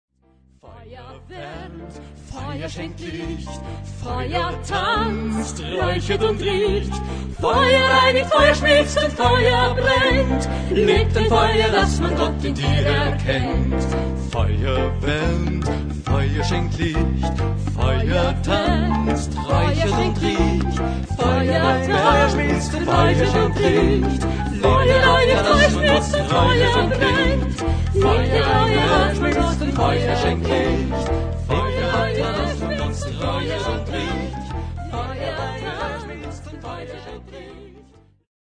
Ihr ausgezeichneter und vielseitiger Pianist